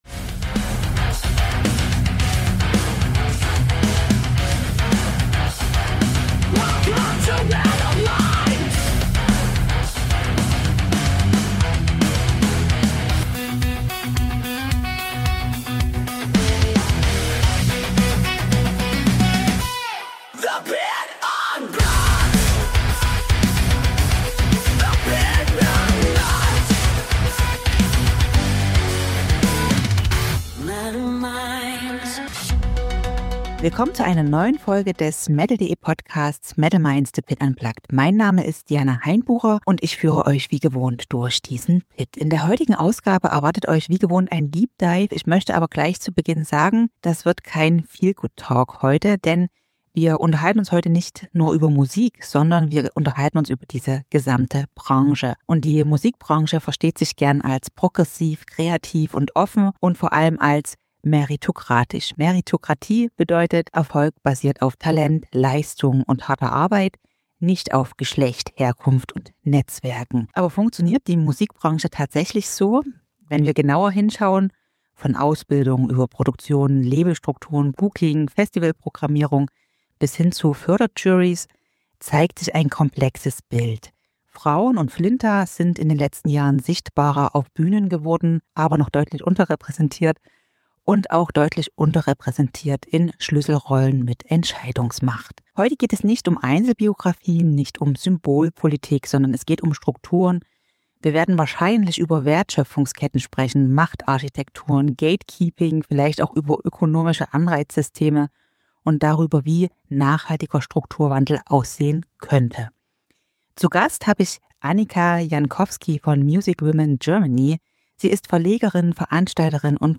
Gemeinsam diskutieren sie, wie kulturelle Rollenbilder, Netzwerkeffekte und informelle Gatekeeping-Strukturen Karrieren beeinflussen, insbesondere in männlich codierten Genres wie Rock, Metal und Punk. Gleichzeitig geht es um konkrete Hebel für Veränderung: transparente Entscheidungsprozesse, diversere Produktionsstrukturen, familienfreundlichere Arbeitsmodelle und die Frage, wie echter Strukturwandel in der Musikbranche aussehen kann.